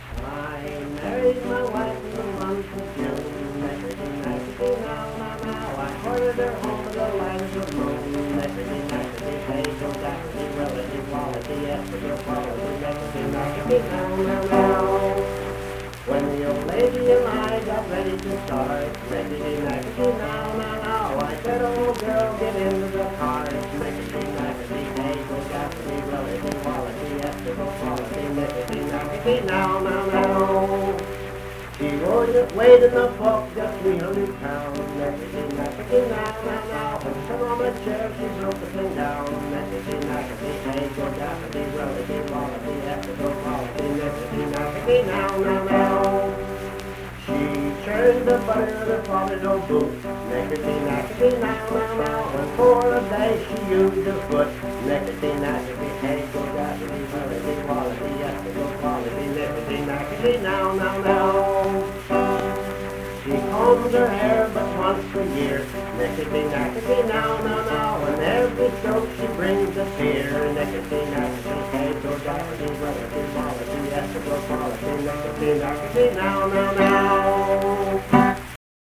Accompanied vocal and guitar music
Verse-refrain 5(6w/R).
Performed in Hundred, Wetzel County, WV.
Guitar, Voice (sung)